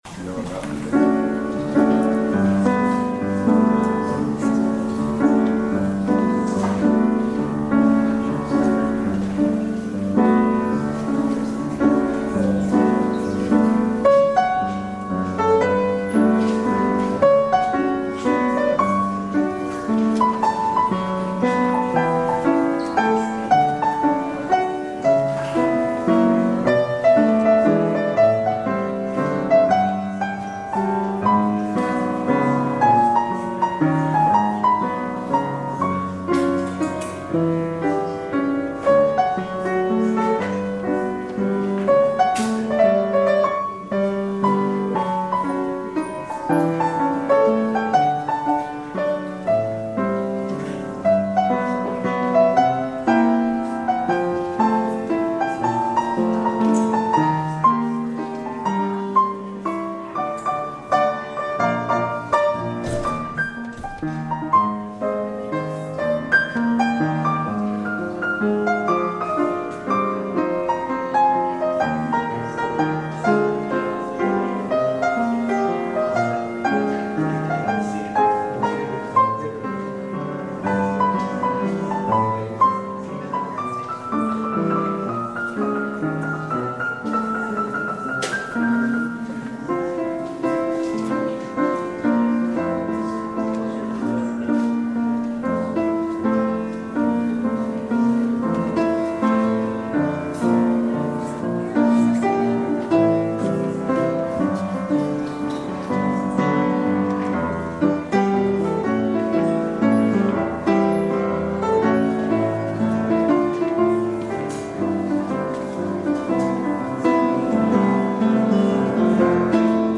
Audio recording of the 10am service, held in the Parish Hall